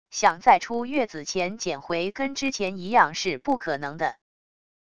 想在出月子前减回跟之前一样是不可能的wav音频生成系统WAV Audio Player